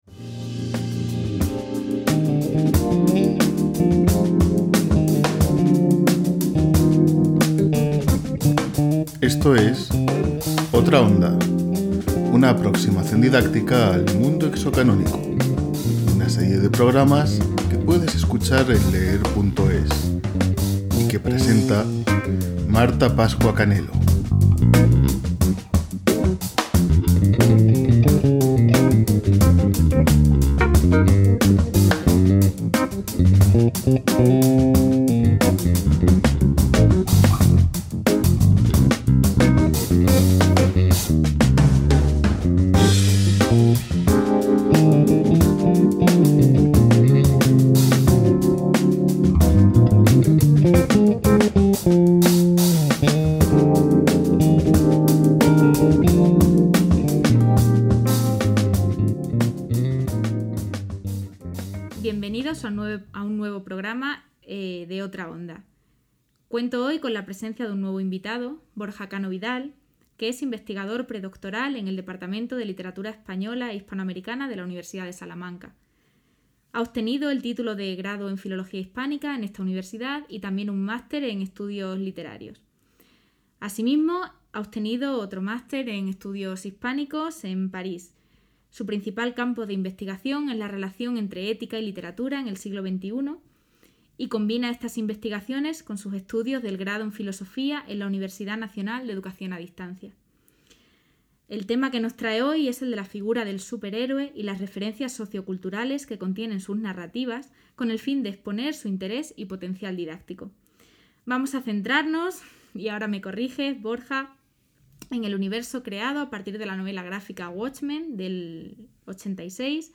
Esta serie de pódcasts ofrece a los docentes y estudiantes interesados siete capítulos en los que varios expertos de la Universidad de Salamanca son entrevistados para hablar de las ventajas y posibilidades educativas de introducir en el aula objetos culturales ajenos al canon cultural dominante.